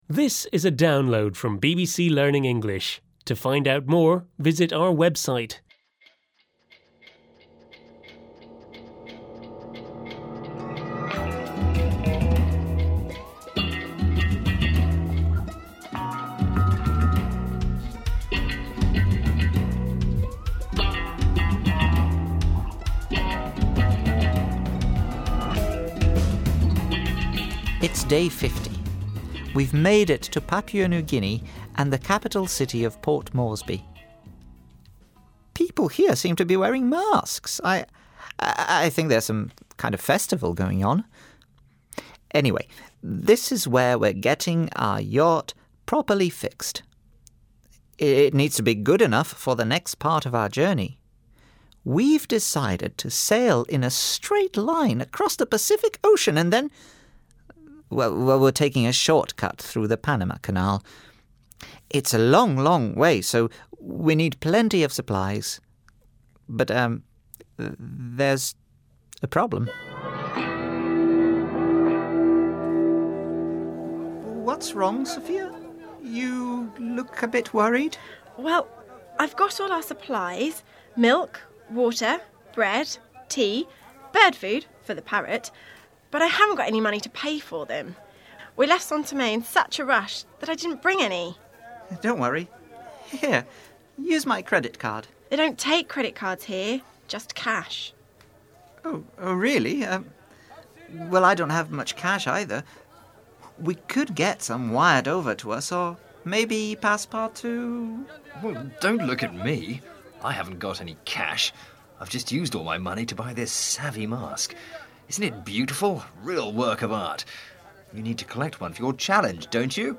unit-8-5-1-u8_eltdrama_therace_download.mp3